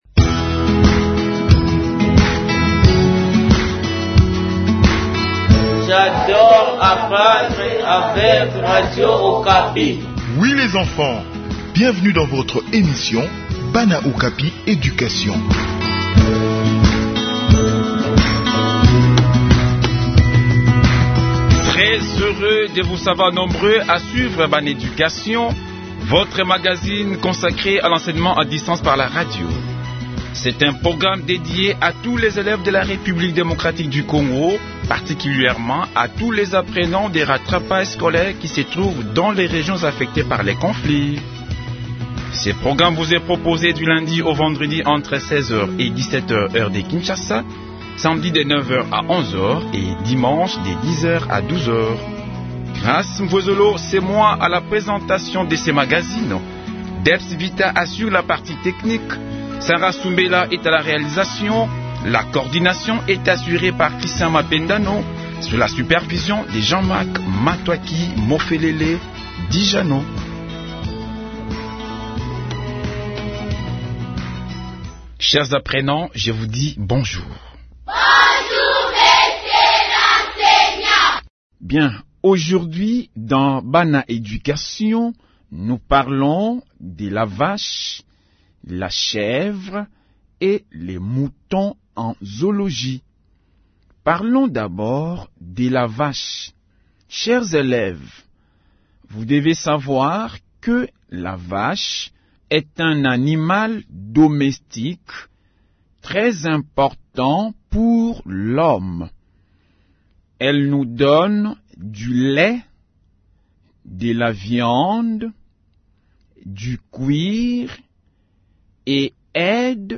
Enseignement à distance : leçon sur la vache, la chèvre et le mouton